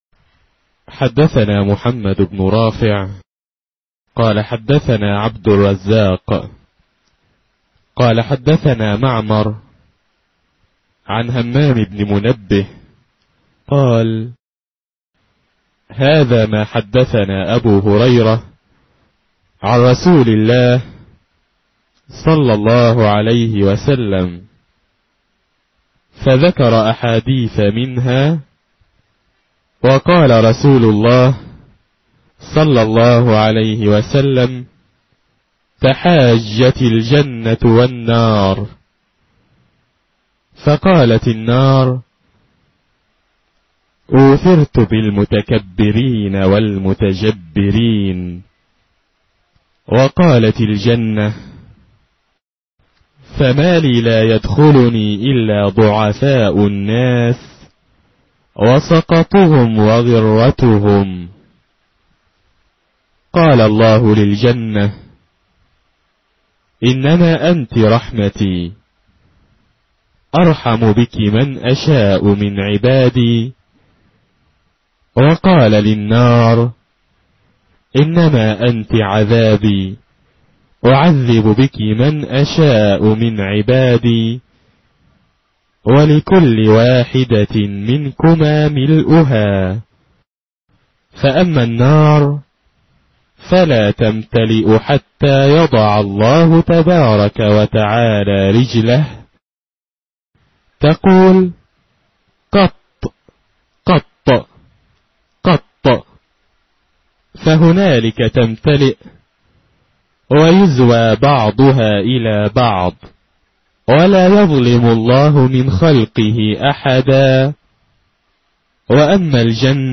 الـكتب الناطقة باللغة العربية